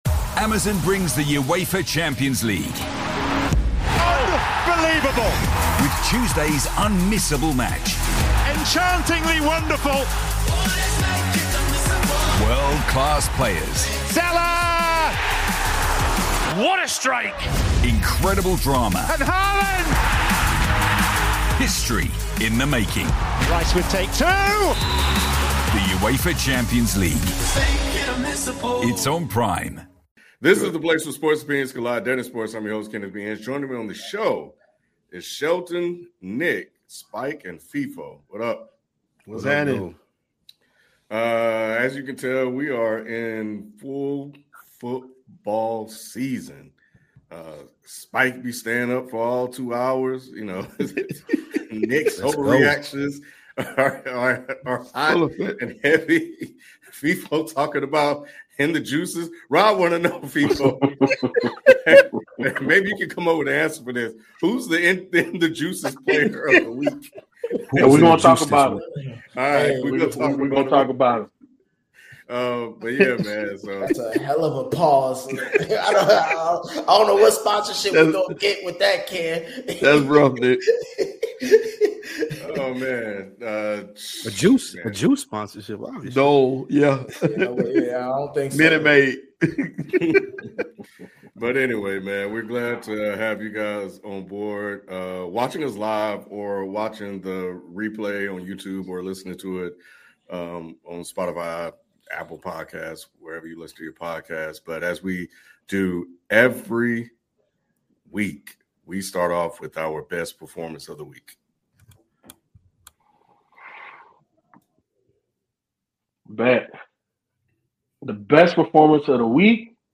Dead End Sports is back LIVE tonight at 9PM ET with a jam-packed NFL show!